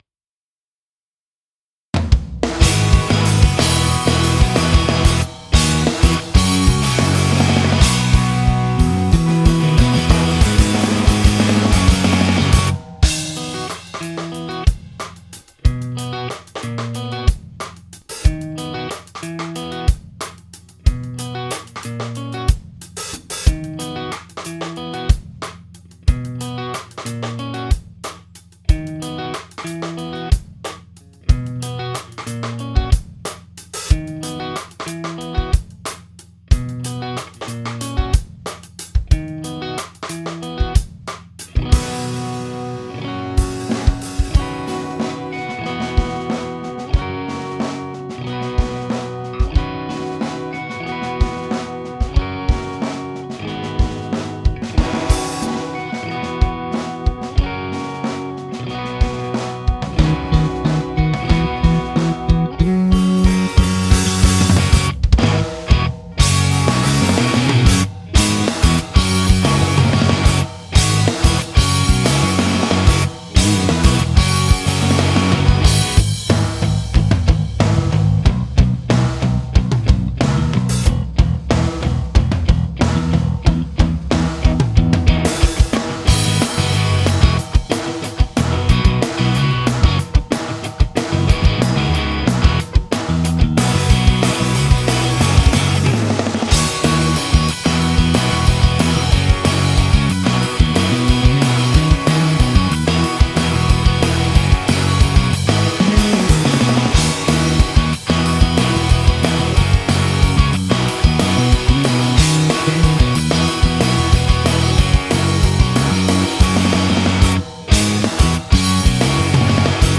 オリジナルKey：「B